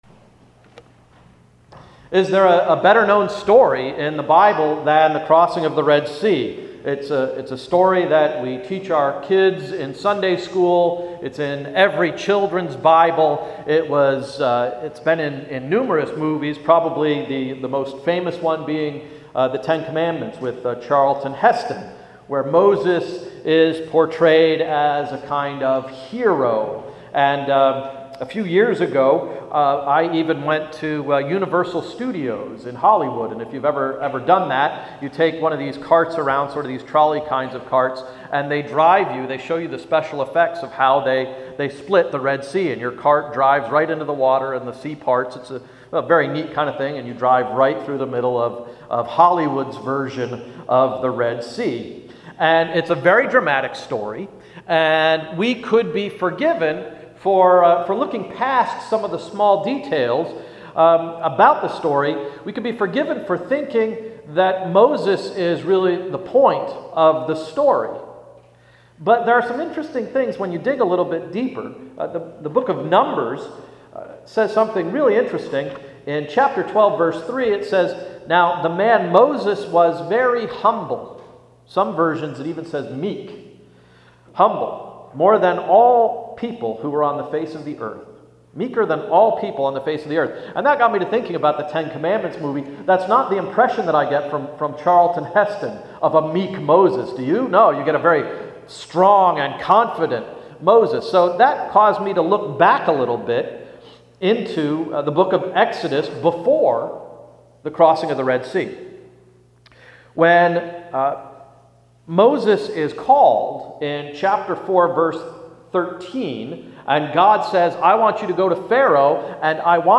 Sermon of September 11–“When Moses Led . . .”